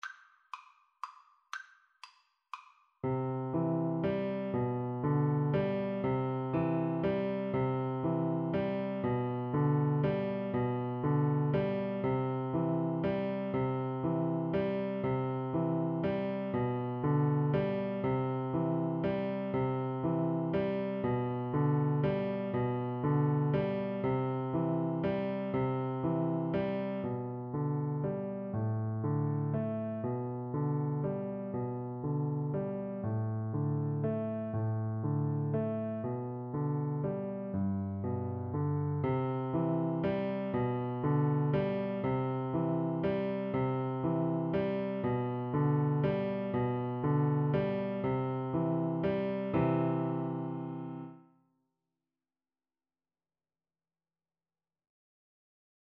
Traditional Trad. Girls and Boys Come Out to Play (English Traditional) Piano Four Hands (Piano Duet) version
3/4 (View more 3/4 Music)
C major (Sounding Pitch) (View more C major Music for Piano Duet )
Brightly = c. 120